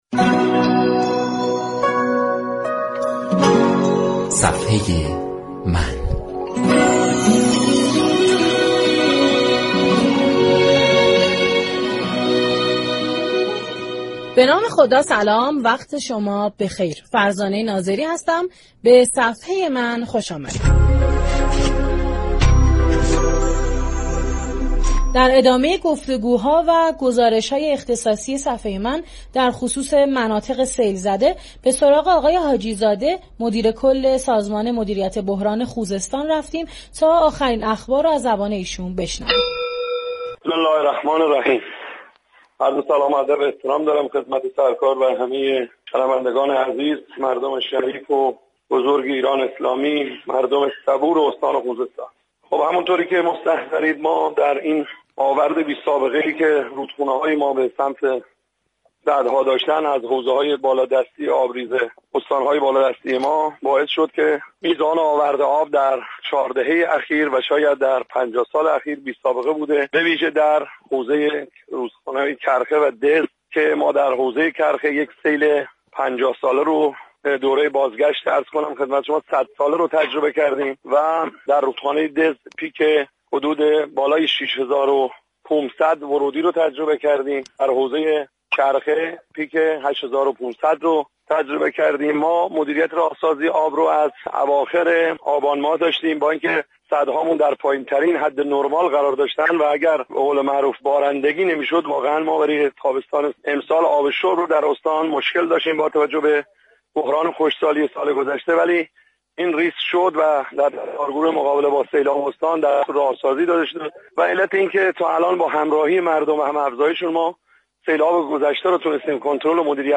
مدیركل مدیریت بحران استان خوزستان در بخش صفحه من رادیو ایران گفت: تمام تلاش خود را می كنیم كه كانالها باز شوند و جلوی آب به شهرهای بزرگ خوزستان را بگیریم.